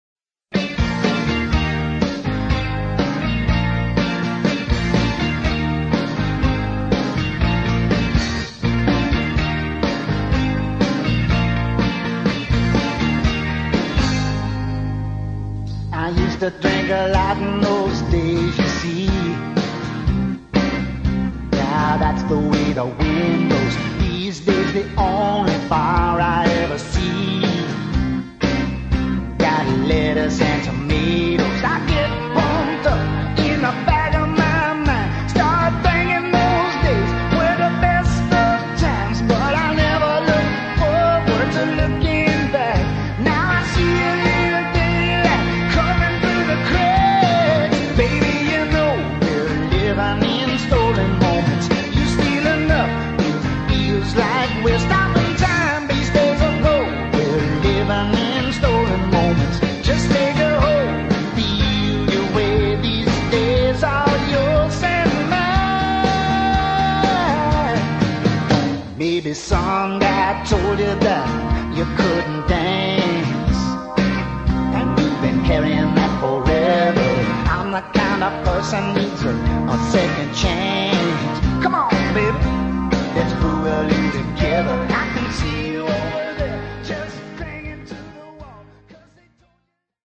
please note: demo recording
location: Nashville, date: unknown